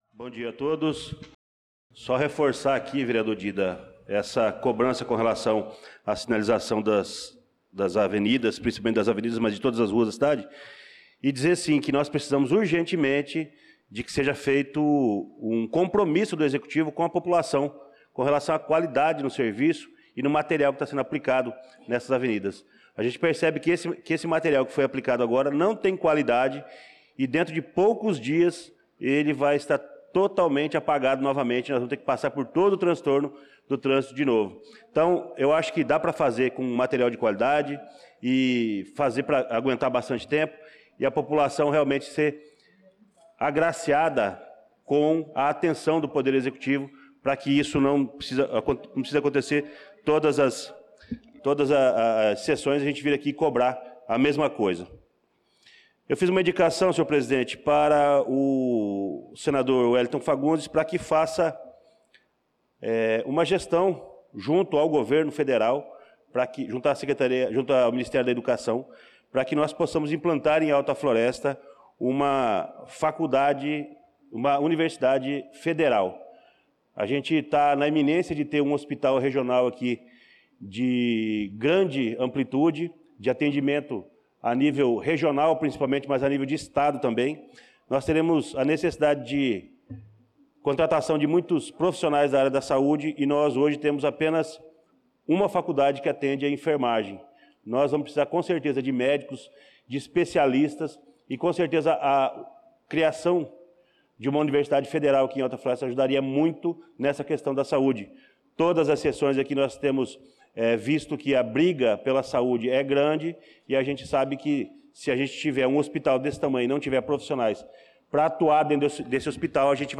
Pronunciamento do vereador Luciano Silva na Sessão Ordinária do dia 18/08/2025.